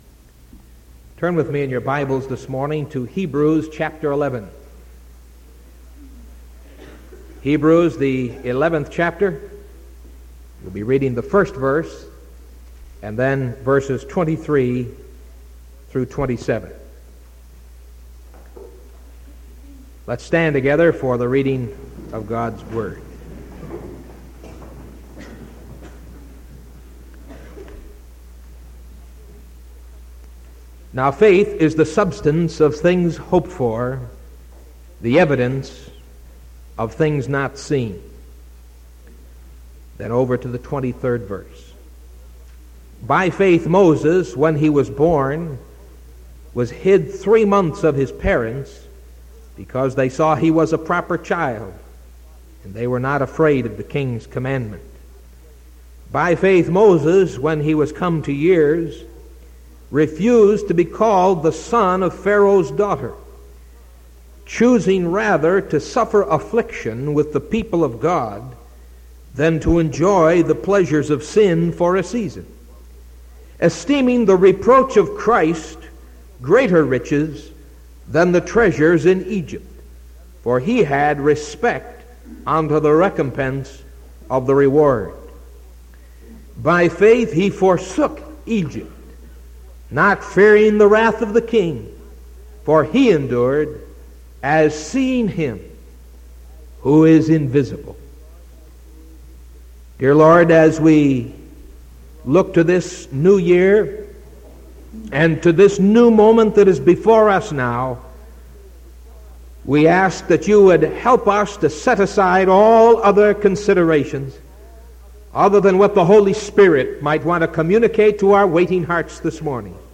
Sermon from December 29th 1974 AM